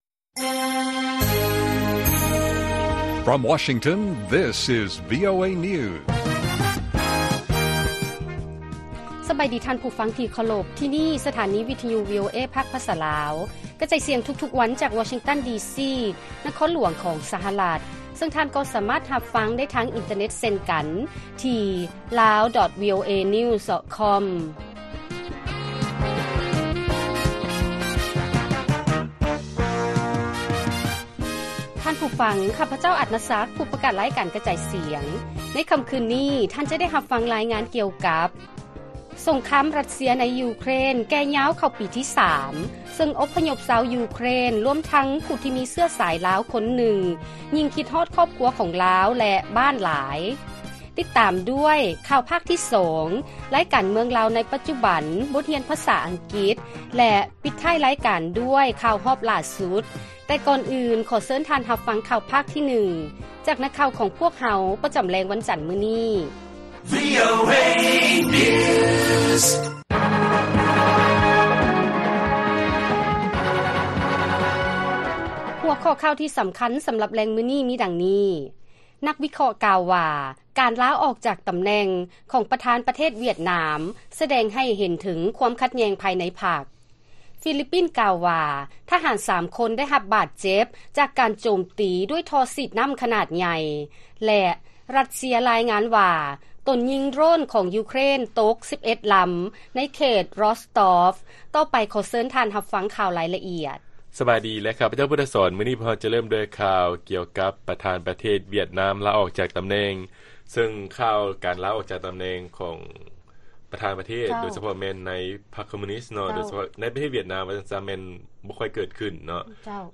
ລາຍການກະຈາຍສຽງຂອງວີໂອເອ ລາວ: ສົງຄາມຣັດເຊຍໃນຢູເຄຣນ ກ້າວເຂົ້າປີທີສາມ, ອົບພະຍົບຊາວຢູເຄຣນ ລວມທັງຜູ້ທີ່ມີເຊື້ອສາຍລາວ ຍິ່ງຄິດຮອດຄອບຄົວ ແລະບ້ານຫຼາຍ